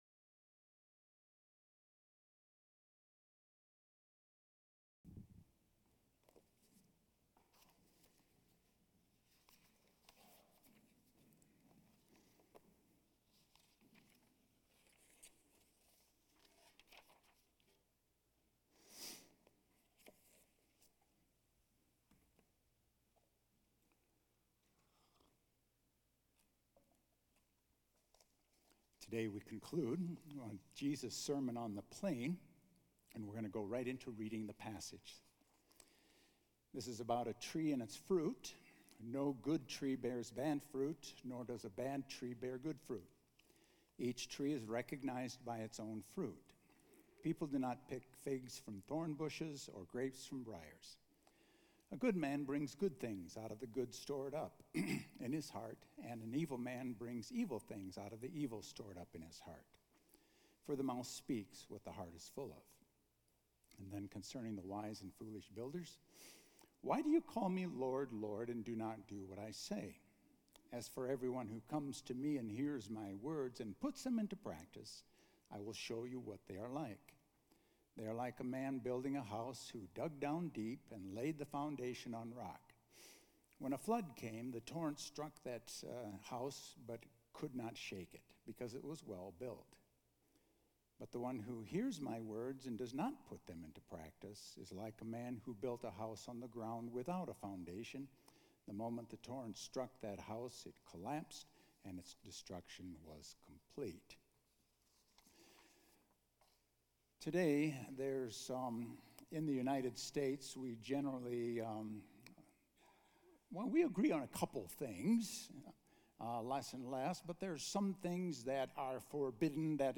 A message from the series "Sermon on the Plain."